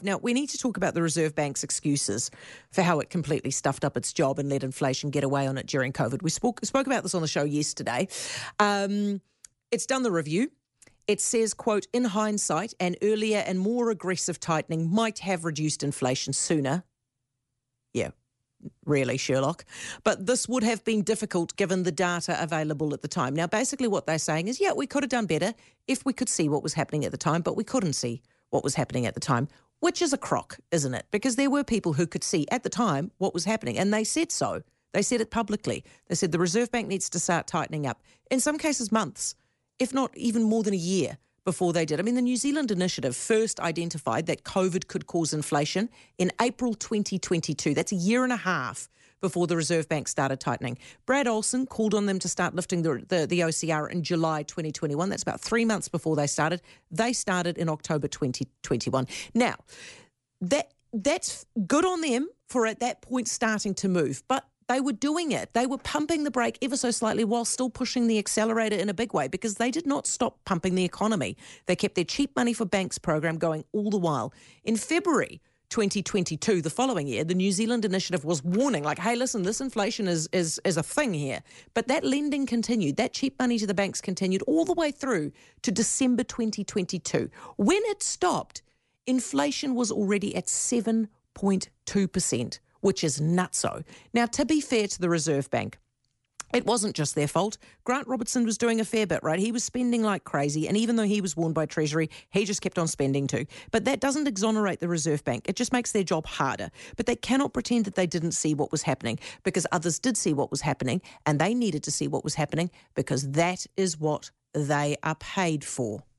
Heather du Plessis-Allan discussed the Reserve Bank's handling of inflation during COVID on Newstalk ZB, highlighting that The New Zealand Initiative first identified that COVID could cause inflation a year and a half before the Reserve Bank began tightening in October 2021.